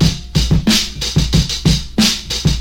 92 Bpm Drum Groove E Key.wav
Free drum loop - kick tuned to the E note.
92-bpm-drum-groove-e-key-kh0.ogg